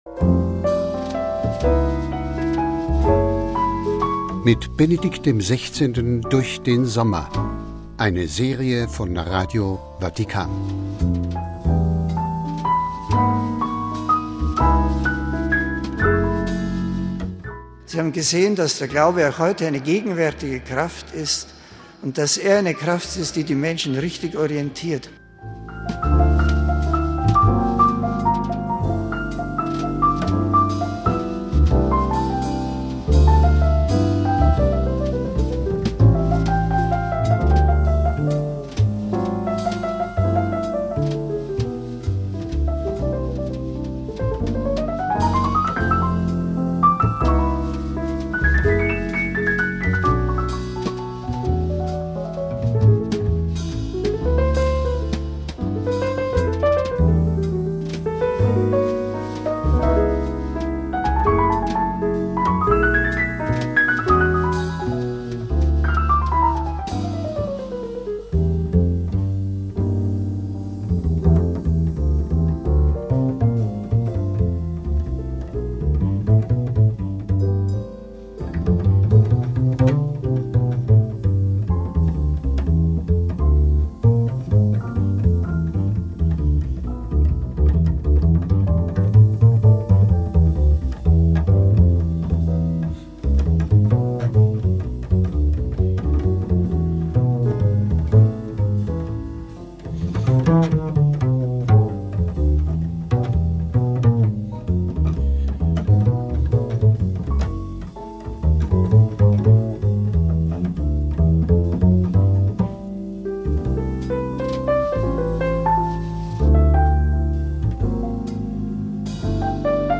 Wir starten heute nämlich unsere Serie "Mit Papst Benedikt durch den Sommer". Unsere "Papst-Häppchen" stammen aus der Frage-Antwort-Stunde, die das Kirchenoberhaupt bei seinem Ferienaufenthalt letztes Jahr in Brixen bei einem Treffen mit Priestern hielt.